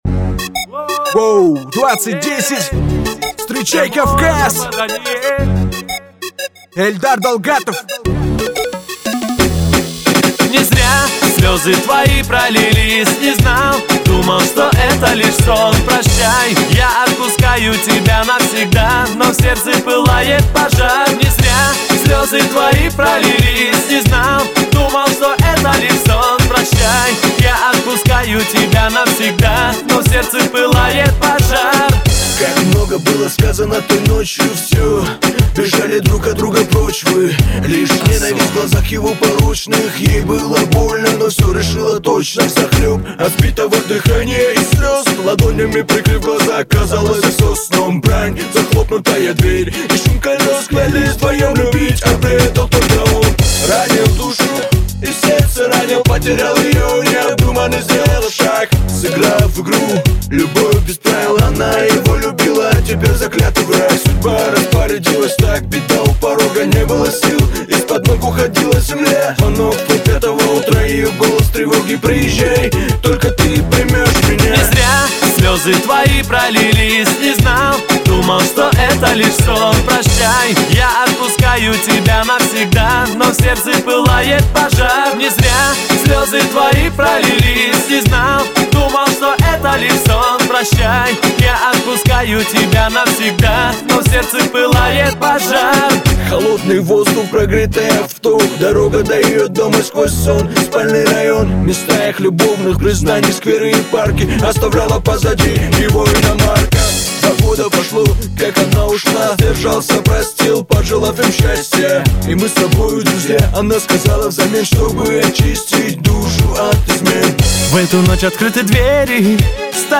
Категория: Реп